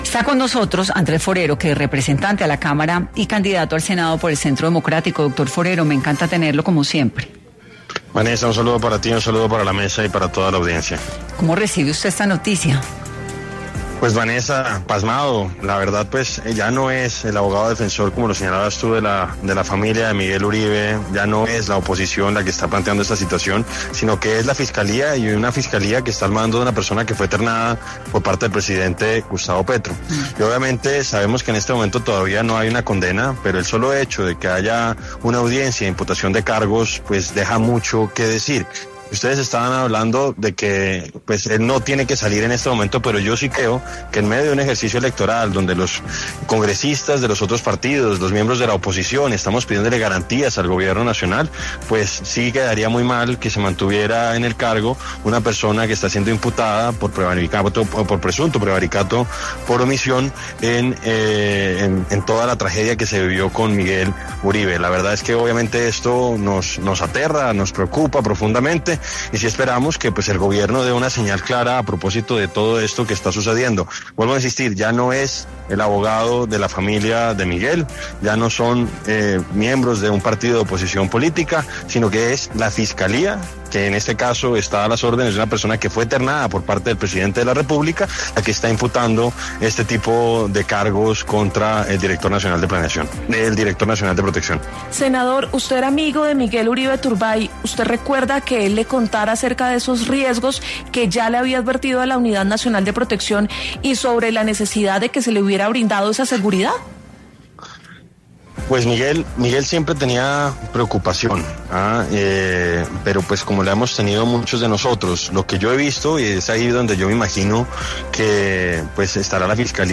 Durante el noticiero del mediodía de Caracol Radio con Juan Diego Alvira, el ministro de Trabajo, Antonio Sanguino, aseguró que el aumento de cargos no responde a intereses políticos ni electorales, sino a una necesidad operativa del Estado frente a los cambios que del país, donde influye la implementación de las reformas pensional y laboral.